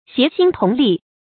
協心同力 注音： ㄒㄧㄝ ˊ ㄒㄧㄣ ㄊㄨㄙˊ ㄌㄧˋ 讀音讀法： 意思解釋： 見「協心戮力」。